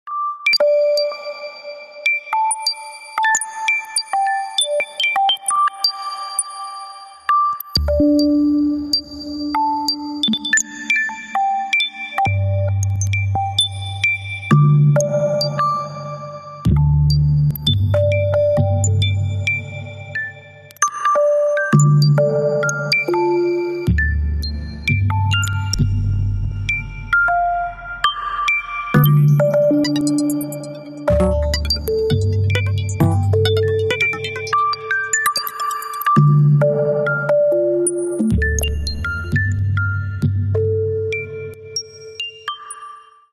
Электроакустические опыты